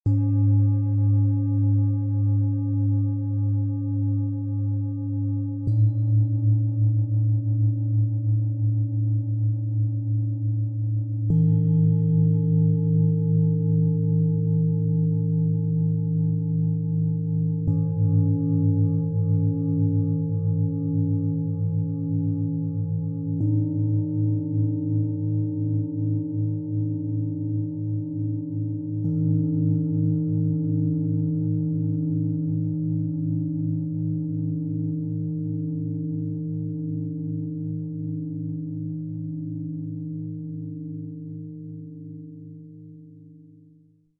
Der inneren Stimme folgen: frische Ideen spüren, Herz und Bauchgefühl leben - Set aus 3 Planetenschalen für Klangmassage und Meditationen, Ø 19,9 -23,3 cm, 3,26 kg
Die besondere Qualität dieses Sets liegt in seiner sanften, nach innen gehenden und lösenden Schwingung.
Tiefster Ton: Uranus, Merkur, DNA - Innere Erneuerung und bewusste Ausrichtung
Mittlerer Ton: Mond - Emotionale Stabilität und sanfte Wahrnehmung
Höchster Ton: Hopi, Mond - Herzöffnung und bewusste Herzensverbindung
Wenn man eine Klangschale anspielt, haben sie (passenden Klöppel vorausgesetzt) 3 gut hörbare Töne.
Im Sound-Player - Jetzt reinhören kann der Original-Ton dieser drei handgefertigten Schalen angehört werden. Die vollen, harmonischen Schwingungen entfalten sich sanft und laden zur inneren Reise ein.